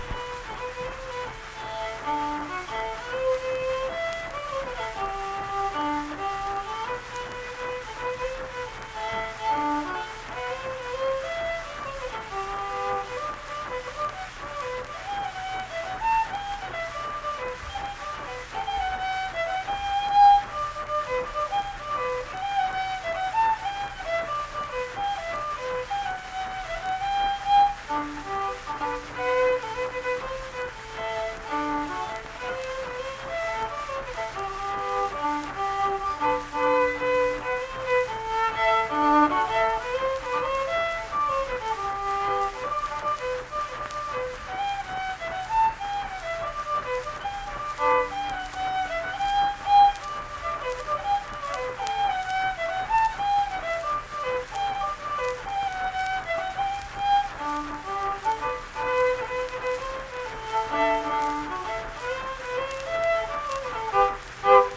Unaccompanied fiddle music
Verse-refrain 3(2).
Instrumental Music
Fiddle